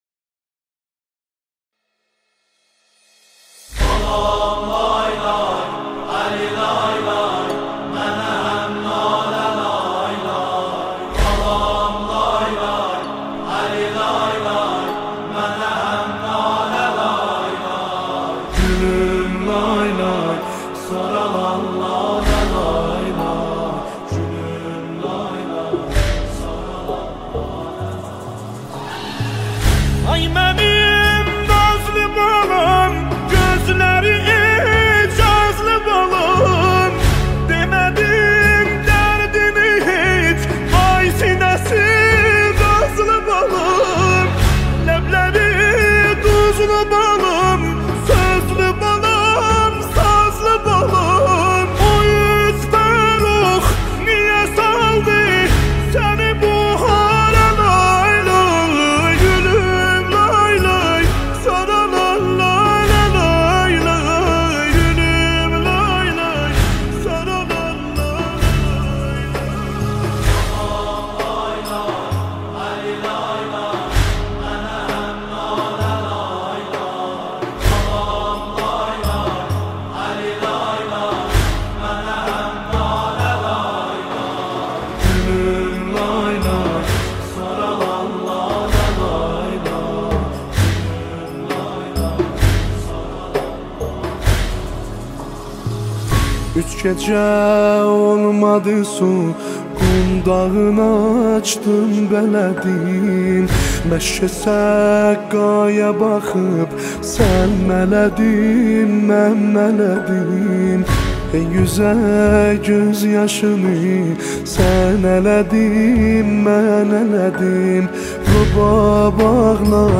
نوحه ترکی
بیس دار